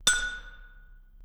ting.wav